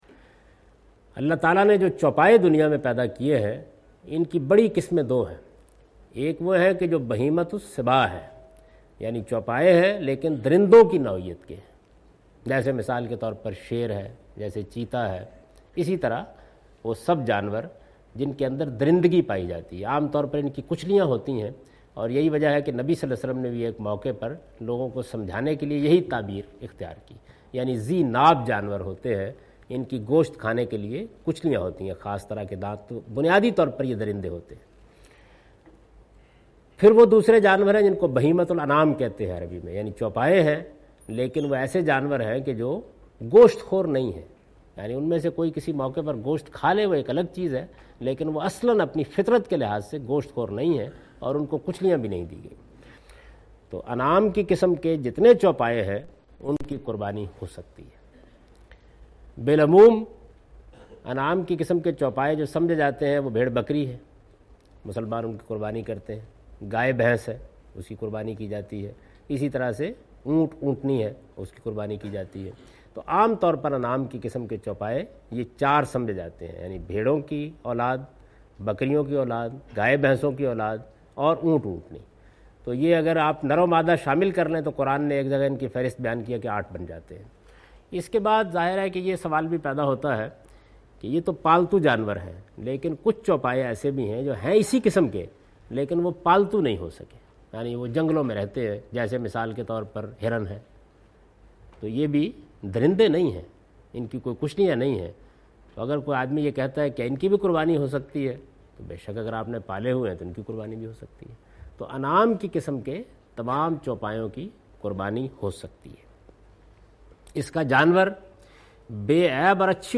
In this video of Hajj and Umrah, Javed Ahmed Ghamdi is talking about "Which Animals can be Sacrificed".
حج و عمرہ کی اس ویڈیو میں جناب جاوید احمد صاحب غامدی "کن جانوروں کی قربانی کی جاسکتی ہے؟" سے متعلق گفتگو کر رہے ہیں۔